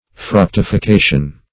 Fructification \Fruc`ti*fi*ca"tion\, n. [L. fructificatio: cf.